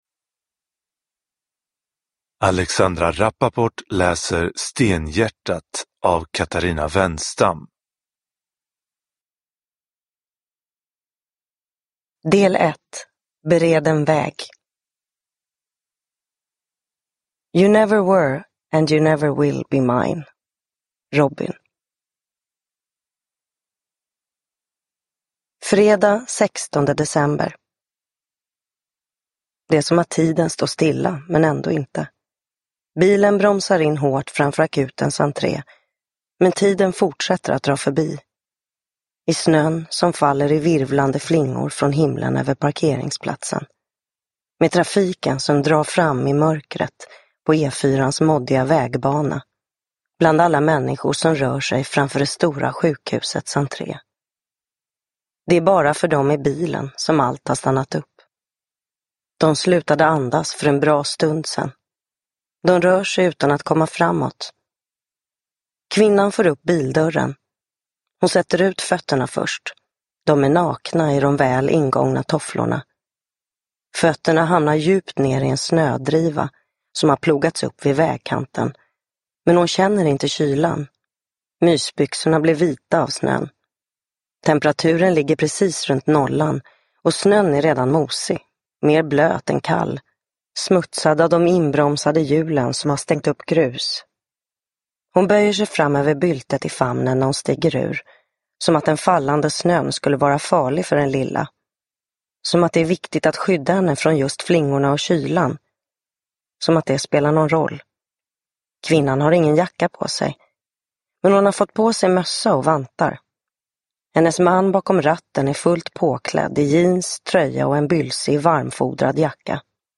Uppläsare: Alexandra Rapaport
Ljudbok